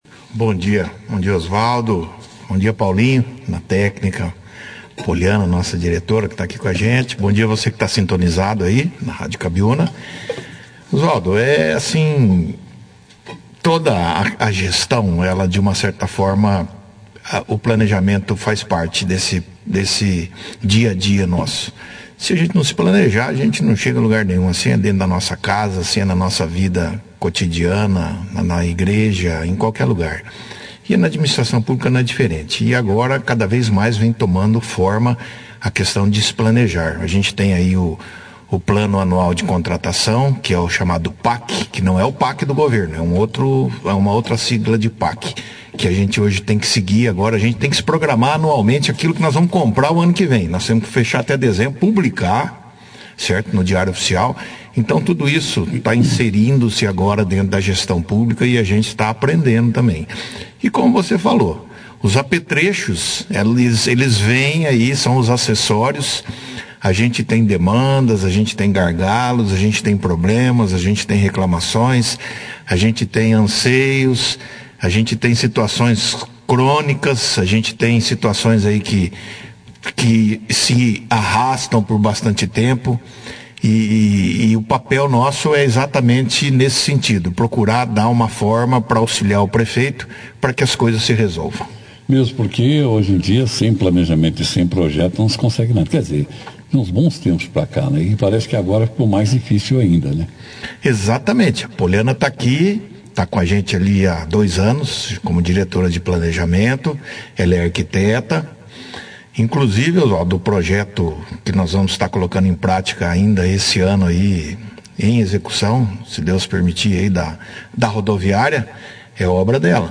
Na entrevista, enfatizaram o empenho unido de toda a equipe de obras e planejando na elaboração de projetos que serão apresentados ao programa ‘Itaipu Mais que Energia’. Este programa promete investimentos significativos em projetos relacionados ao manejo de água e solo, saneamento ambiental, energia renovável e infraestrutura para a comunidade.